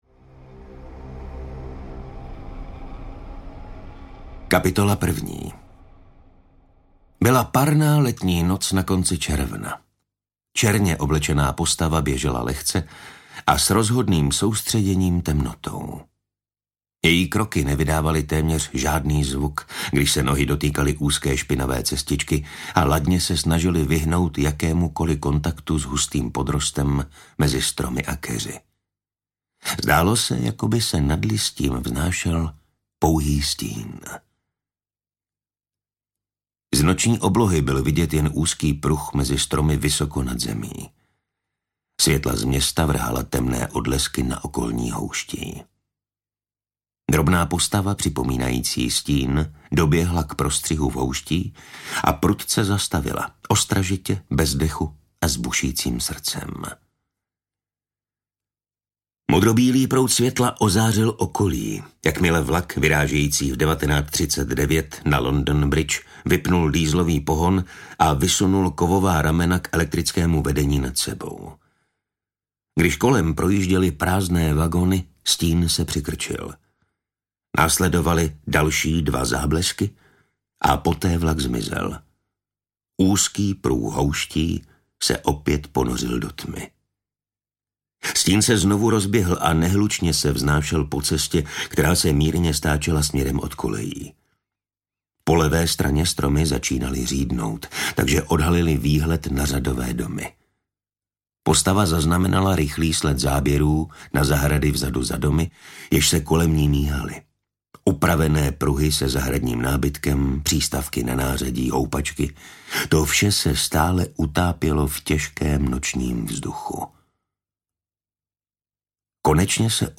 Druhý případ Eriky Fosterové ve skvělém podání oblíbeného herce a dabéra Martina Stránského. Jedné horké červnové noci je detektiv šéfinspektor Erika Fosterová přivolána k brutální vraždě.
Ukázka z knihy
• InterpretMartin Stránský